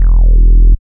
71.05 BASS.wav